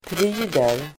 Uttal: [pr'y:der]